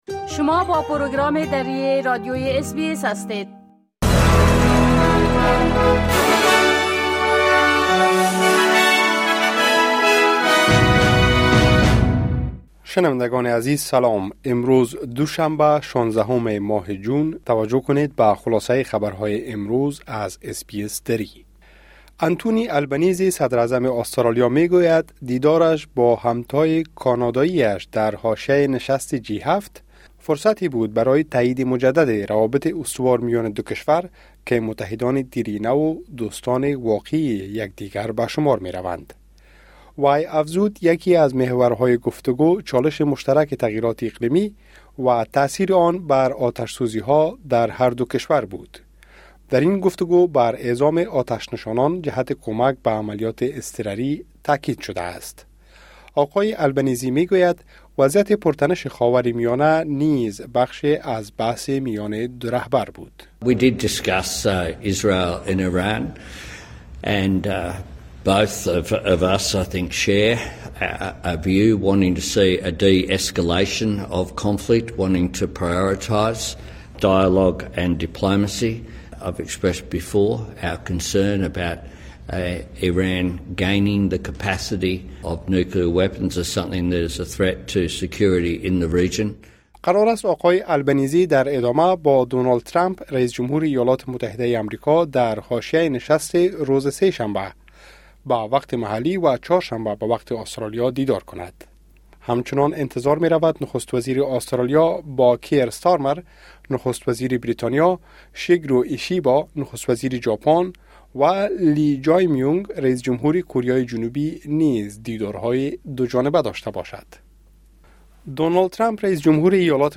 خلاصه مهمترين خبرهای روز از بخش درى راديوى اس‌بى‌اس | ۱۶ جون